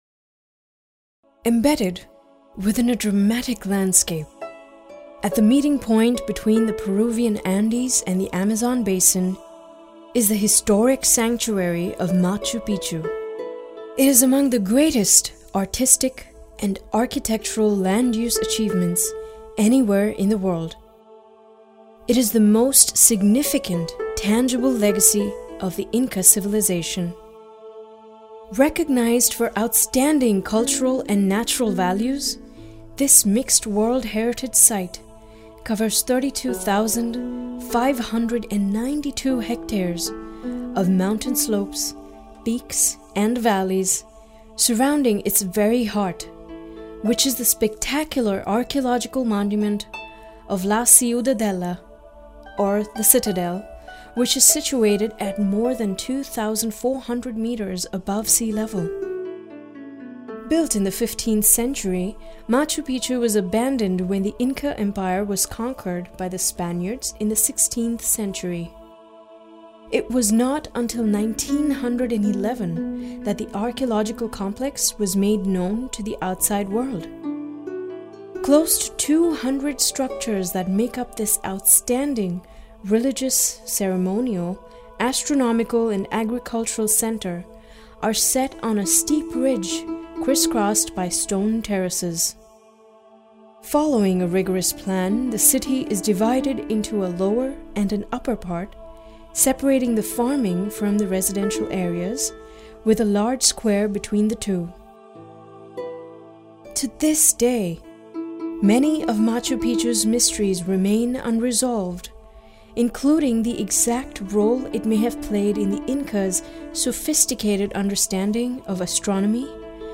Full-time professional VO artist .